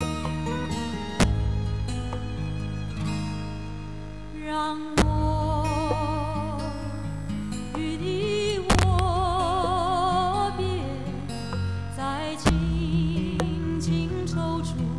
连接到计算机插孔端口的芯片可以录制声音、但外部麦克风无法录制正常声音、声音非常低。
record-from-computer.wav